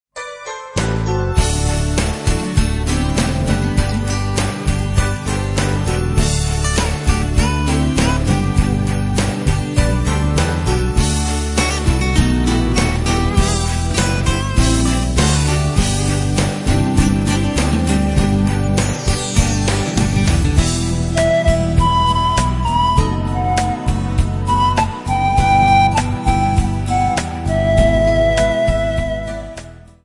A compilation of Christmas Carols from Poland
panpipes